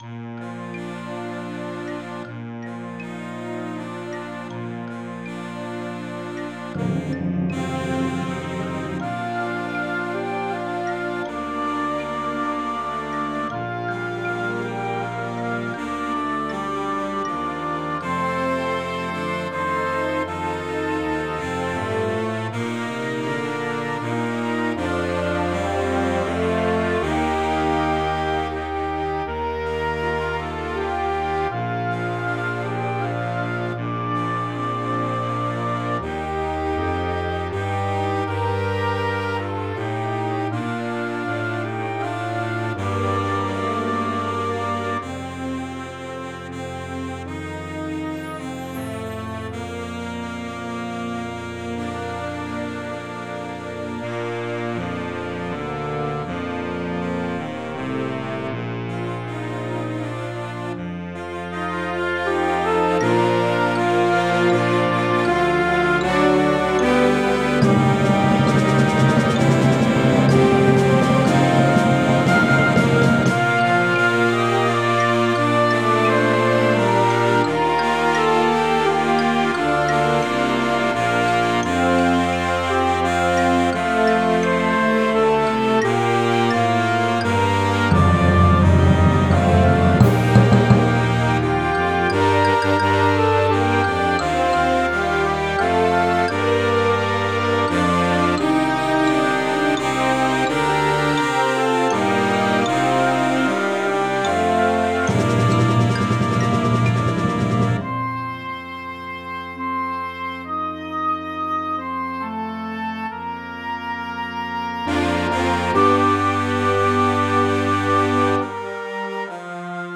flex score arrangement
for Concert Band